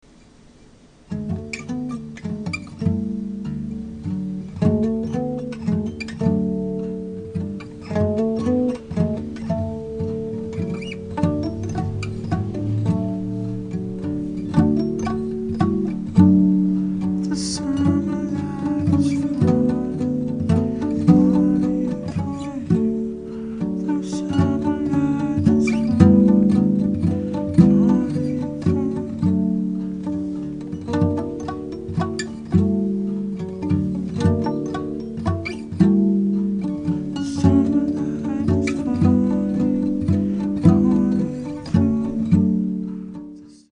is a singer/songwriter based in Los Angeles